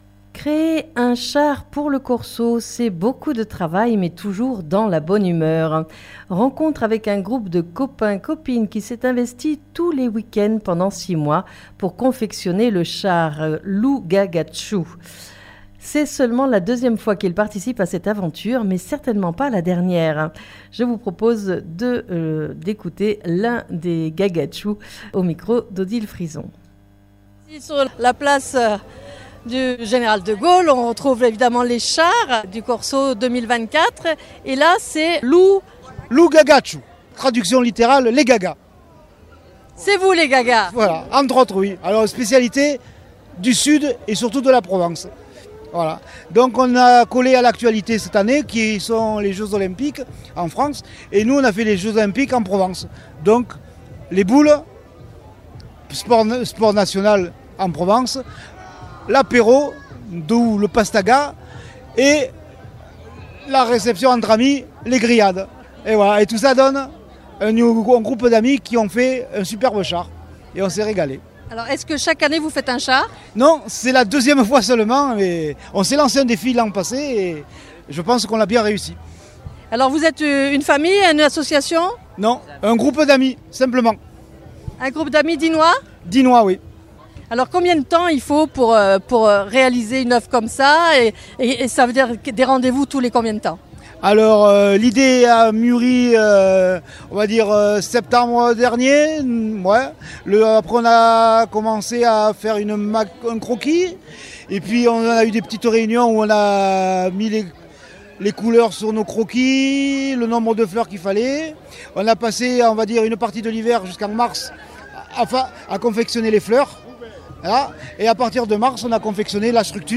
Mardi 6 Aout 2024 Rencontre avec un groupe de copains-copines qui s'est investi tous les weekends pendant 6 mois pour confectionner le char : Lou Gagachou. C'est seulement la 2ème fois qu'ils participent à cette aventure, mais certainement pas la dernière. Reportage